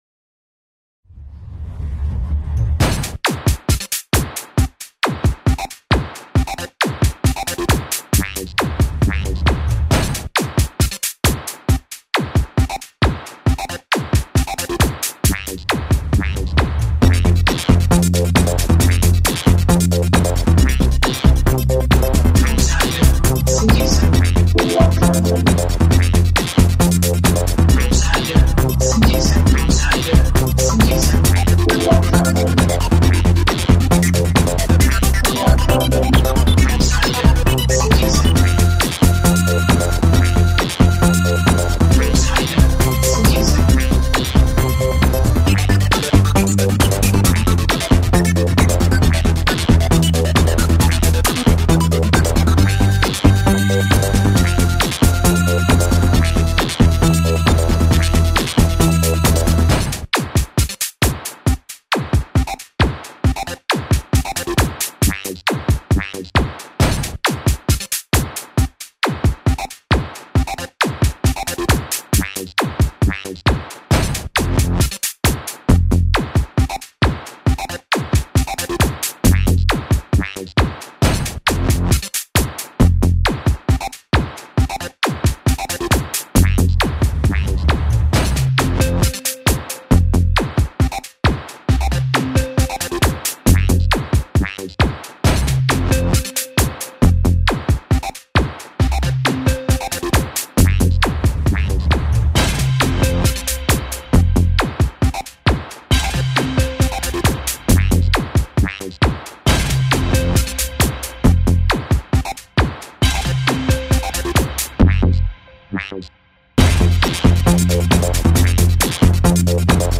Begins with the rumble of the tram. Many of the percussion sounds are constructed from the fieldd recordings – the exceptions being DX100 Solid Bass, basic drums from Native Instruments Battery 4 and Roland Vocoder.